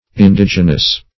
Indigenous \In*dig"e*nous\, a. [L. indigenus, indigena, fr. OL.
indigenous.mp3